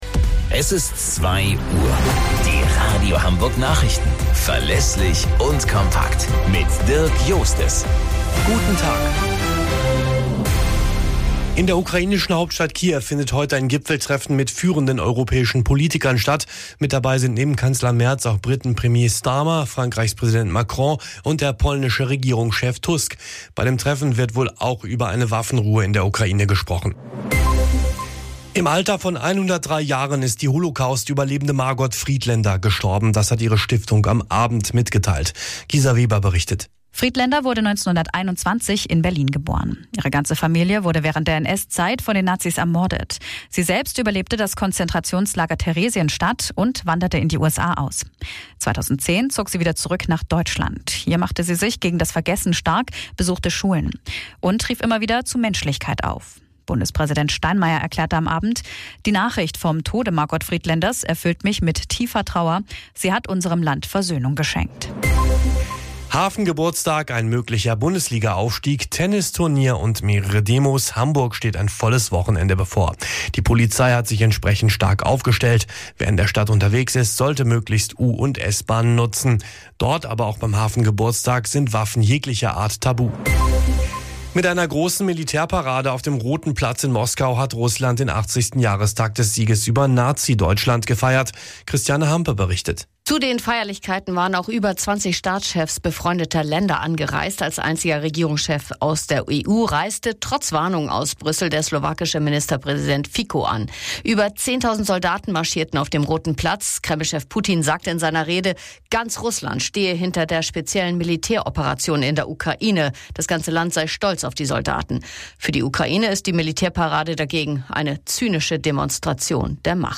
Radio Hamburg Nachrichten vom 10.05.2025 um 09 Uhr - 10.05.2025